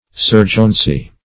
Search Result for " surgeoncy" : The Collaborative International Dictionary of English v.0.48: Surgeoncy \Sur"geon*cy\ (s[^u]r"j[u^]n*s[y^]), n. The office or employment of a surgeon, as in the naval or military service.
surgeoncy.mp3